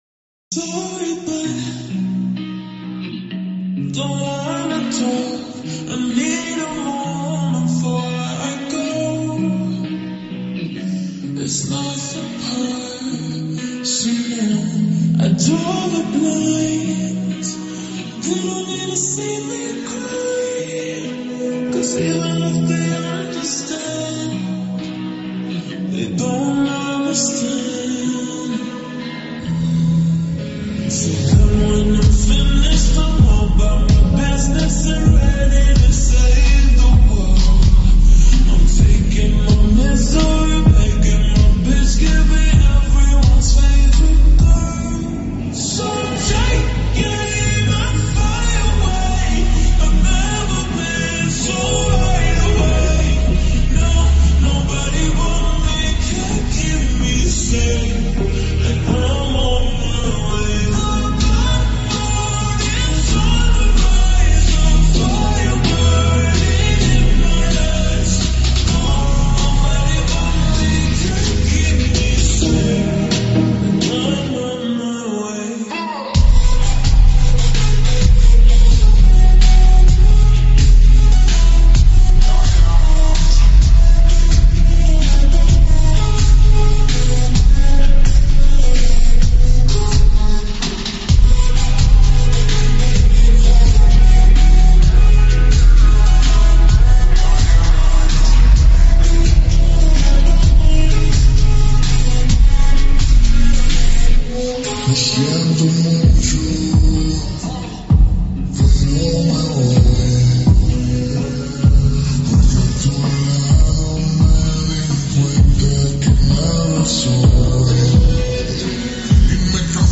sad music